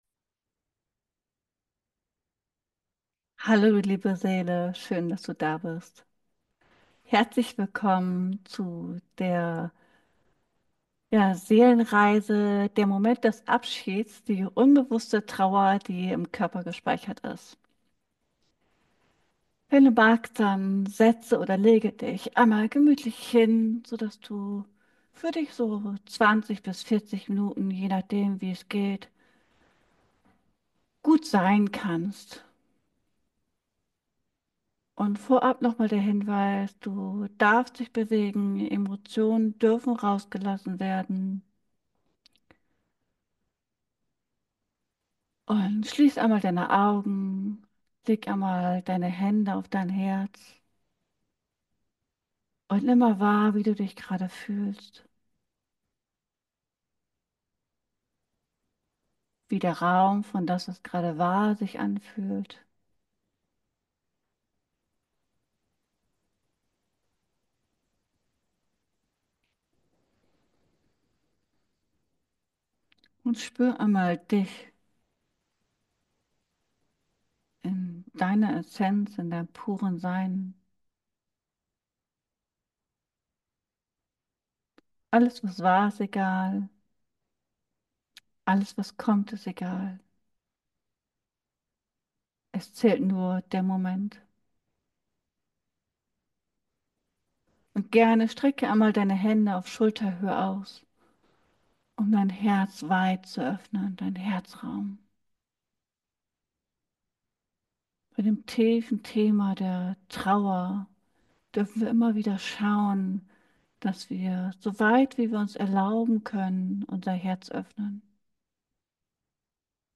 In diesem geführten Video lade ich dich ein, einen tiefen inneren Raum zu betreten. Du kannst dich bequem hinsetzen oder hinlegen und dir 20 bis 40 Minuten Zeit nehmen, um alte Trauer, unbewusste Muster und verborgene Gefühle zu erforschen.